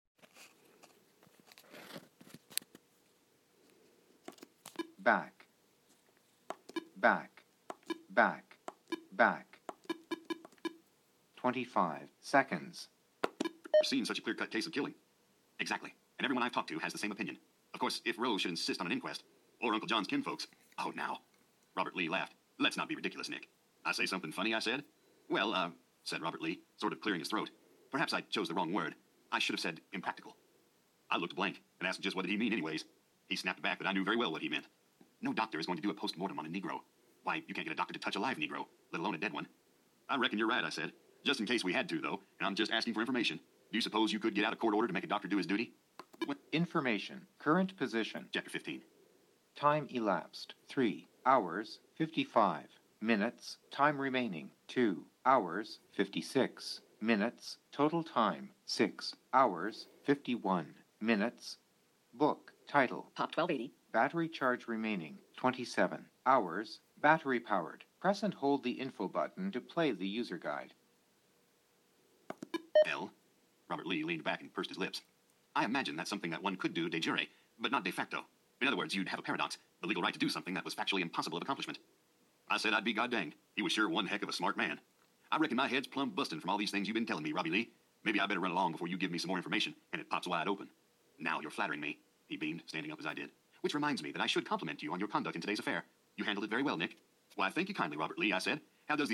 Clip from an audiobook from NLS: Jim Thompson: Pop. 1280 (from 1960)